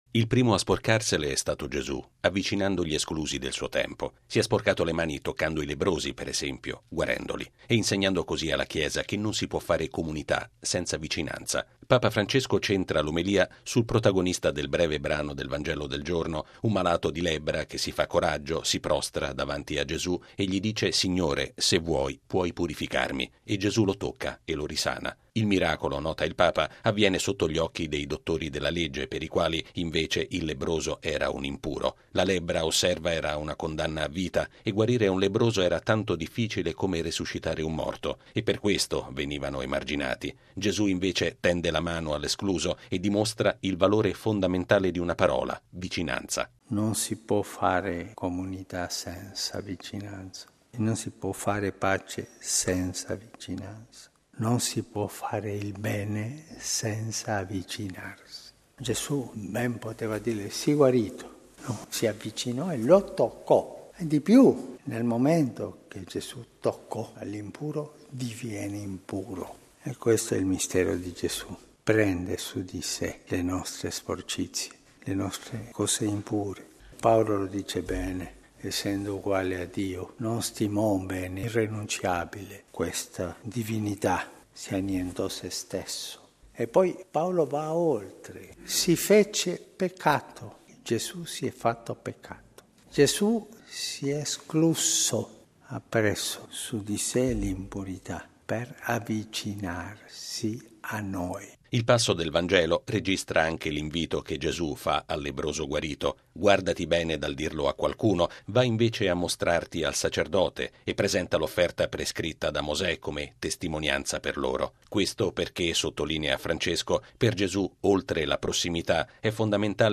Lo ha affermato Papa Francesco all’omelia della Messa in Casa Santa Marta.